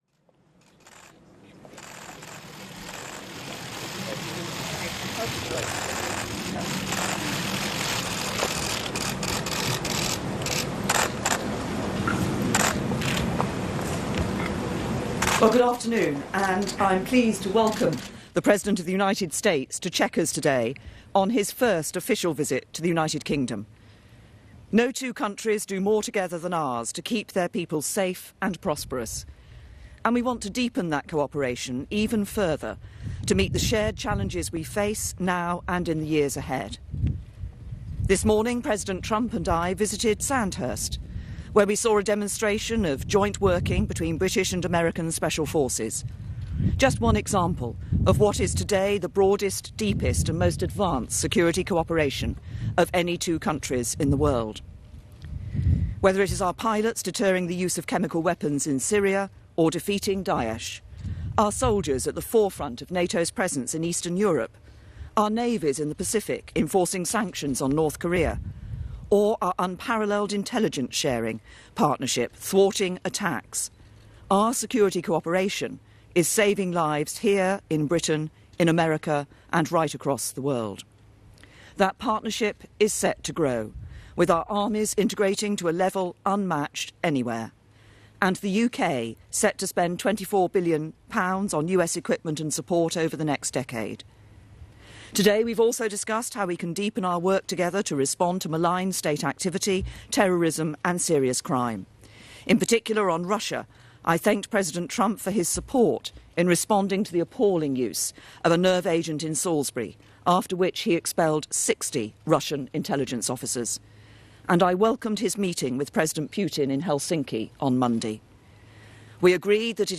U.S. President Donald Trump and British Prime Minister May hold a joint news conference